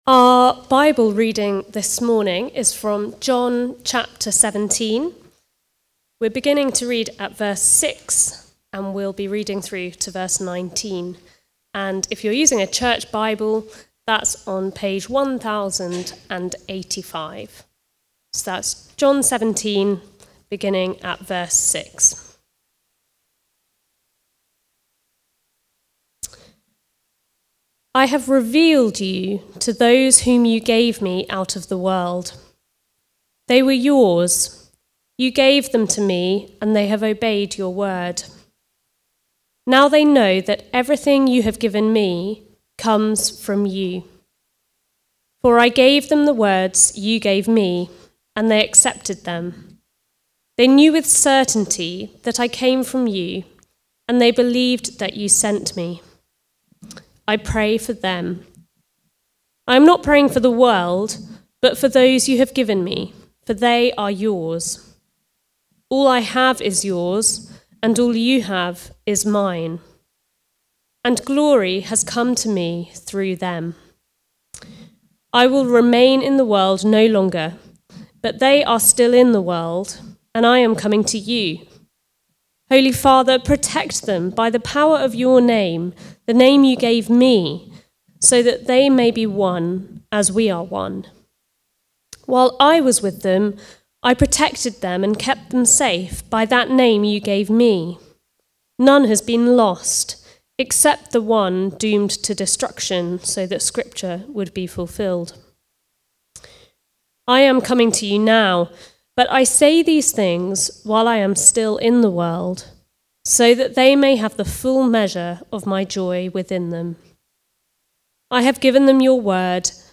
Preaching
The Mission (John 17:6-19) from the series Comfort and Joy. Recorded at Woodstock Road Baptist Church on 30 March 2025.